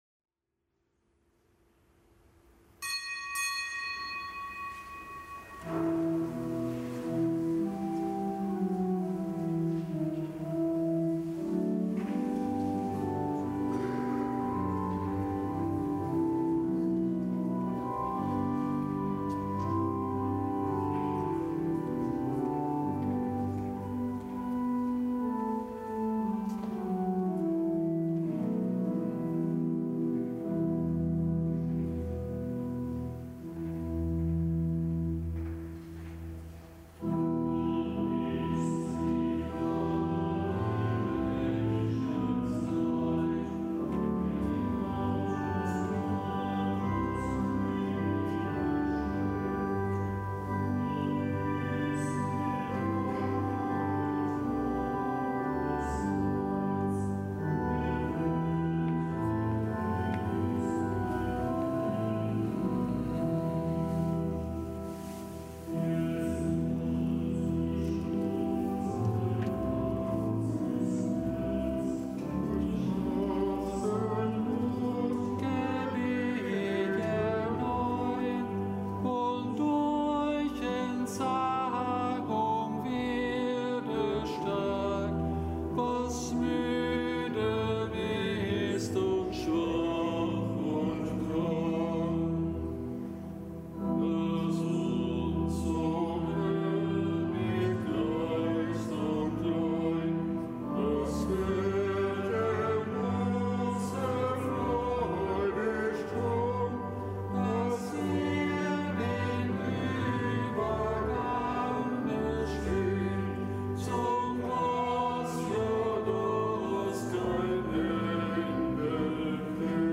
Kapitelsmesse aus dem Kölner Dom am Aschermittwoch.